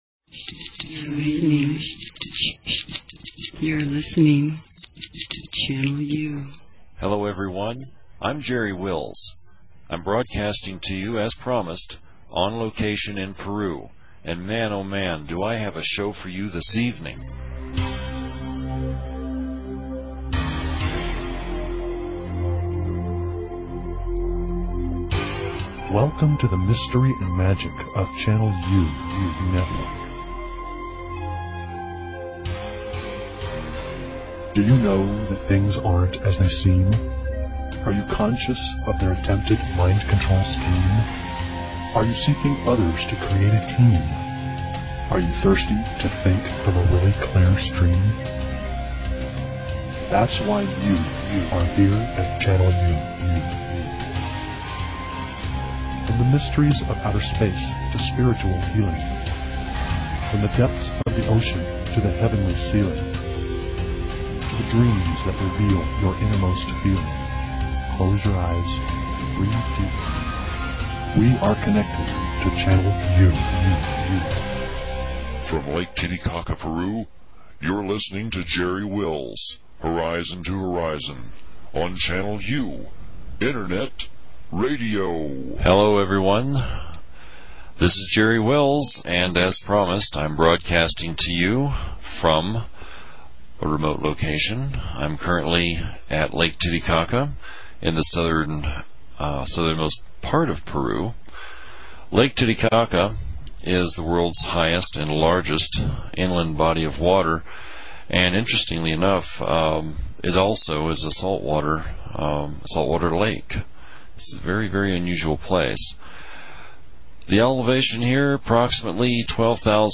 Broadcasting on Location from PERU - A Mysterious Doorway